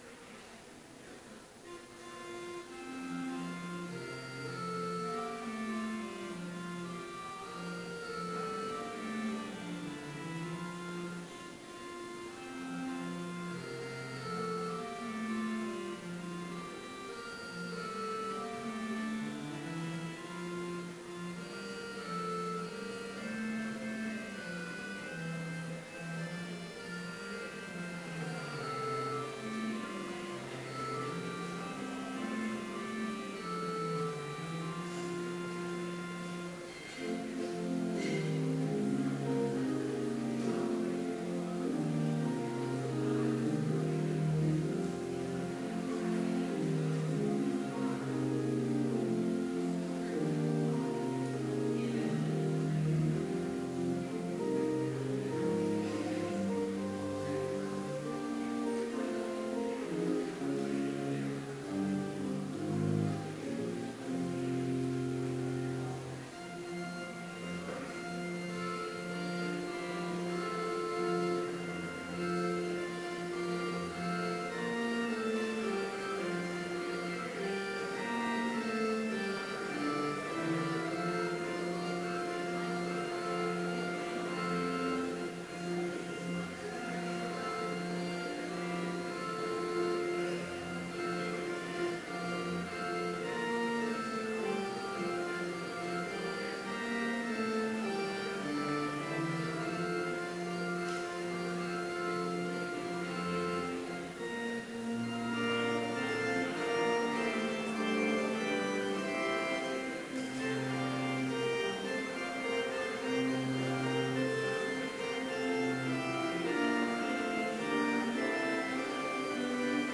Complete service audio for Chapel - April 25, 2016